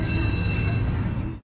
描述：环境在加利福尼亚州洛杉矶格里菲斯公园的旋转木马周围响起。孩子们在远处玩旋转木马的声音。使用内置麦克风和防风屏使用Sony PCMD50录制。
标签： 卡利奥普 旋转木马 格里菲斯霸RK 带机 旋转木马轮 器官 现场录音 立体声 桶器官
声道立体声